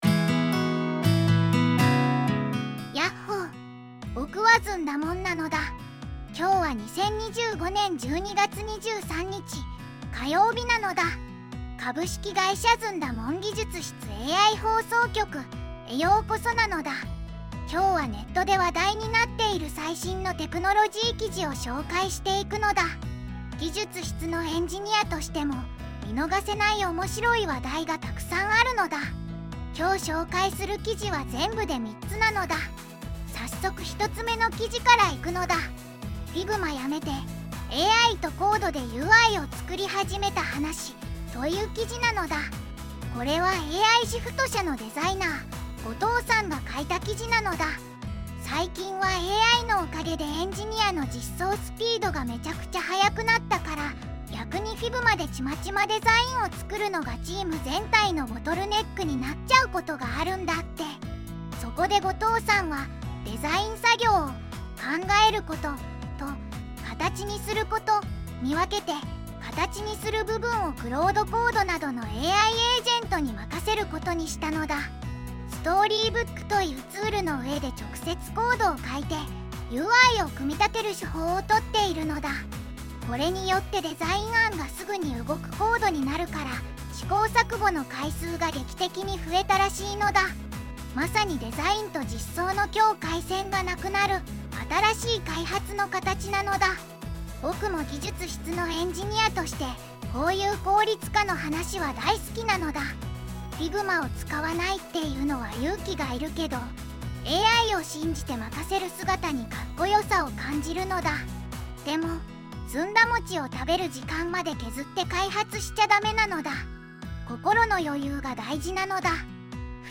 ずんだもん